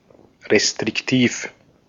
Ääntäminen
Synonyymit streng Ääntäminen Tuntematon aksentti: IPA: /ˌreːstrɪkˈtiːf/ Haettu sana löytyi näillä lähdekielillä: saksa Käännös Adjektiivit 1. restrictive Esimerkit Die Vorschriften werden hier sehr restriktiv gehandhabt.